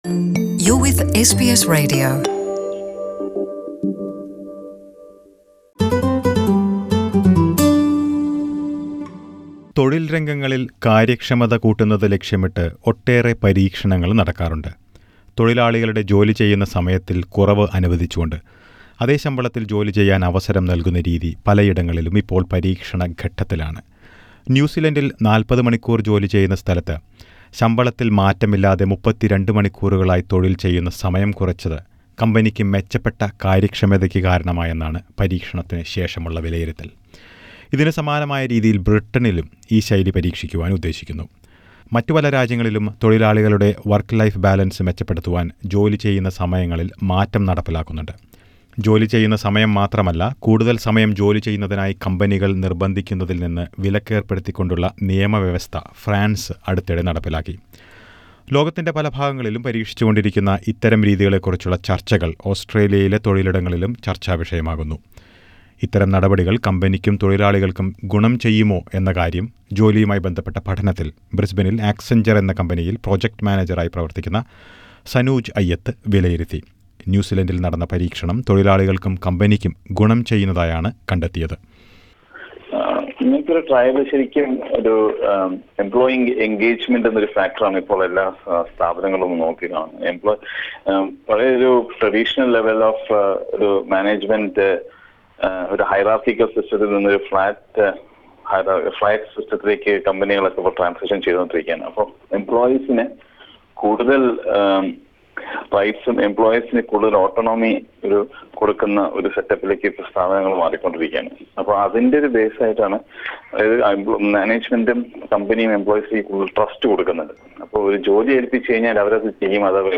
A firm in New Zealand reduced the days of work to 4 and retained the pay for 5 days as a trial for its employees and results appear positive. Some companies in Britain are also planning to do this trial. Listen to a report.